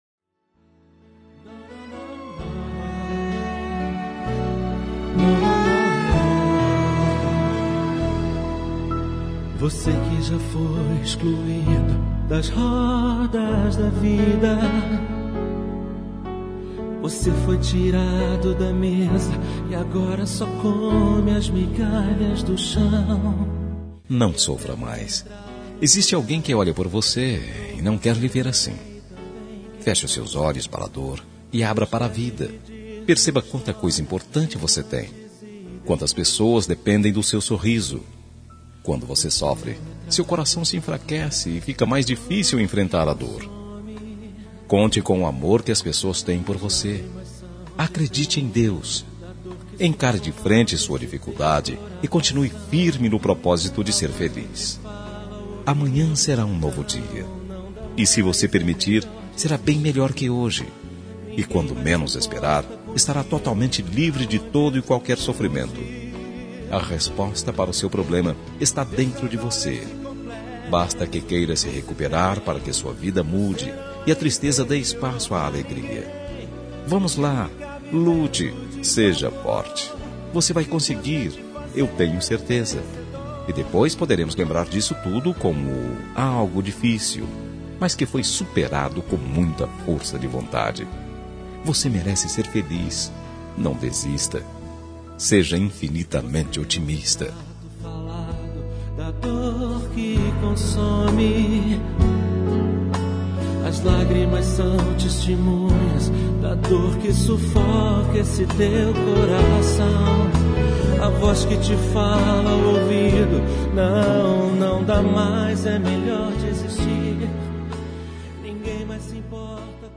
Telemensagem de Otimismo – Voz Feminina – Cód: 188